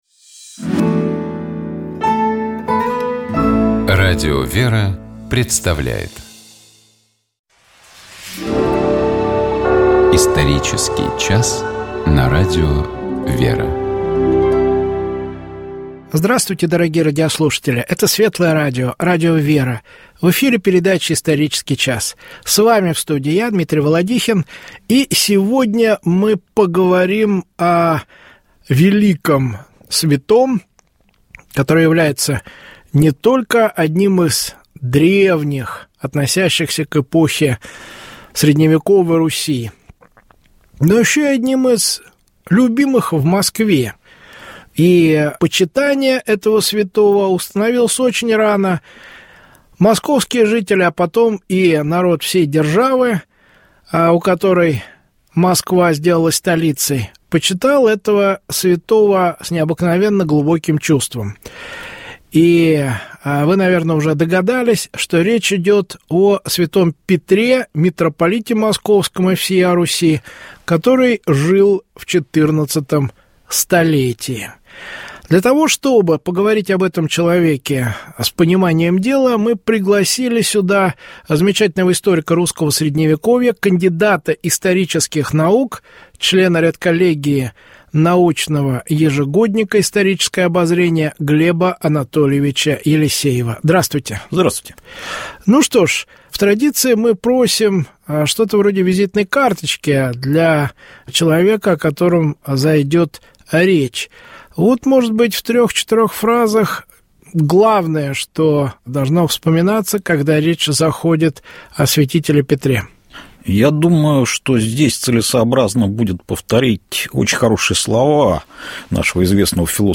Что необходимо сделать для того, чтобы не раздражаться и не злиться на окружающих людей? Ответ на этот вопрос находим в отрывке из 5-й и 6-й глав послания апостола Павла к Галатам, который звучит сегодня за богослужением в православном храме.